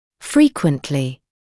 [‘friːkwəntlɪ][‘фриːкуэнтли]часто